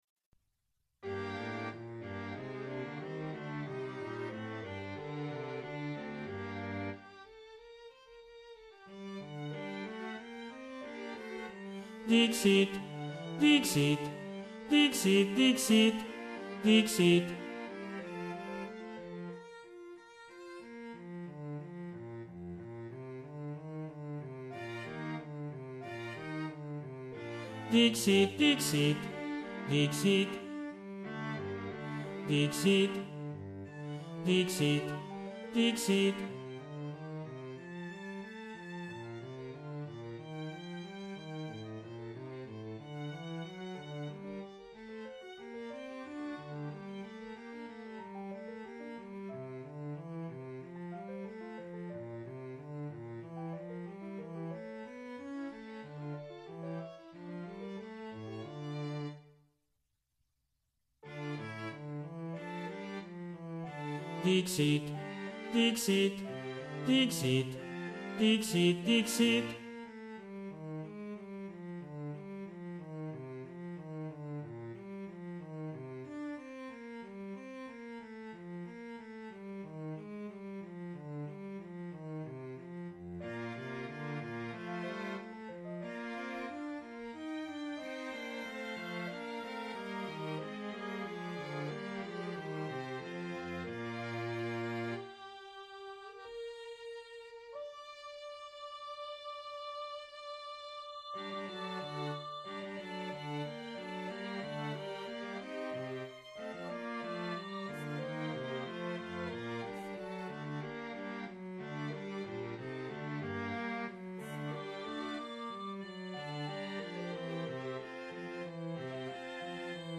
Below you will find rehearsal recordings of the movements, arranged in the order they will be sung at the concerts on December 13 and 14, 2025.
Ténors
Emphasised voice and other voices